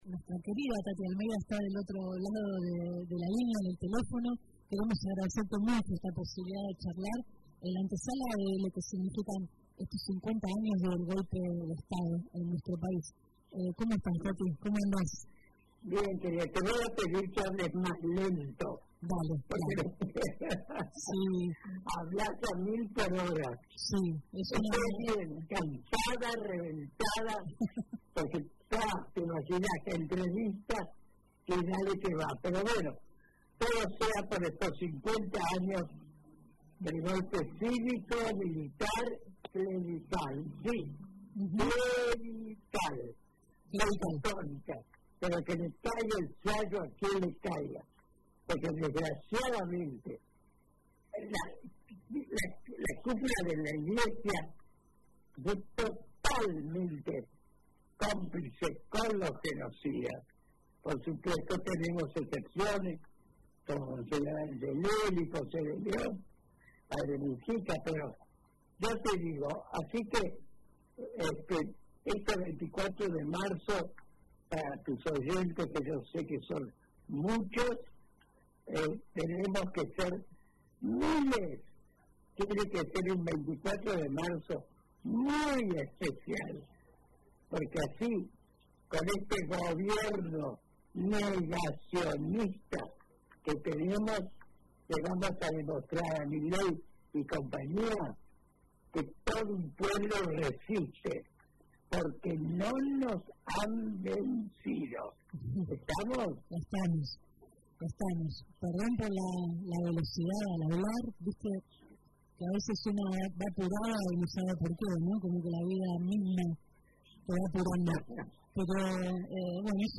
Taty Almeida pasó por Radio UNDAV